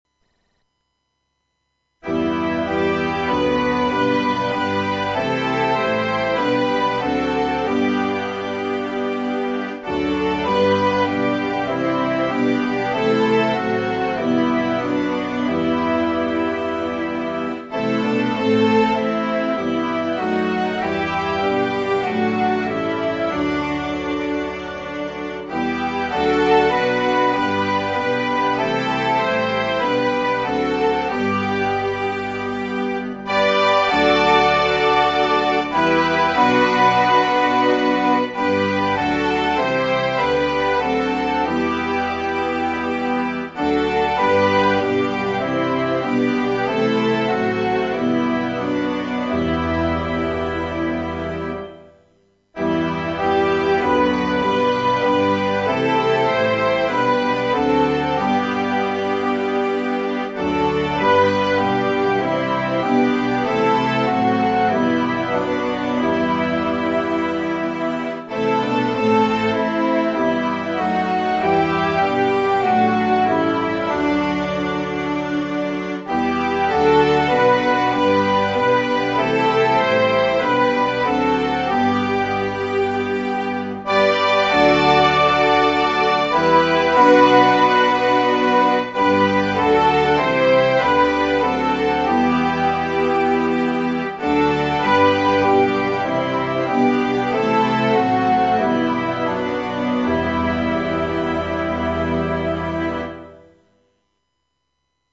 12th　Century　Latin　Hymn